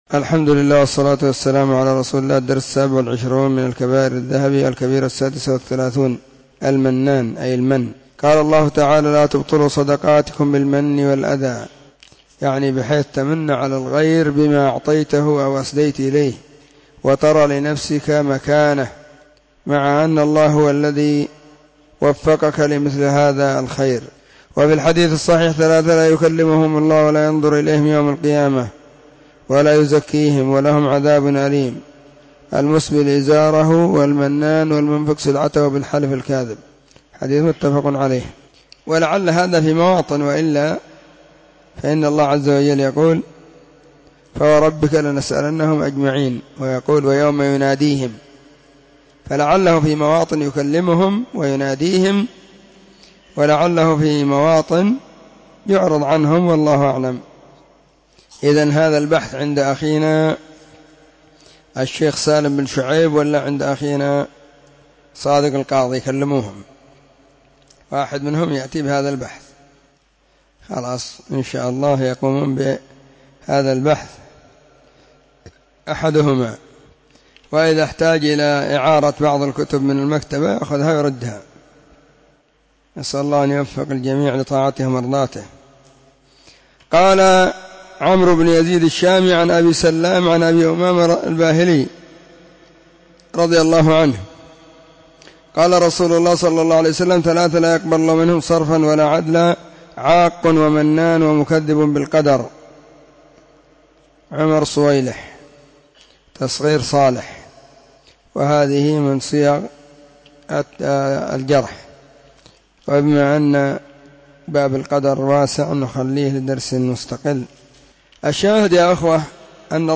📢 مسجد الصحابة – بالغيضة – المهرة، اليمن حرسها الله.
كتاب-الكبائر-الدرس-27.mp3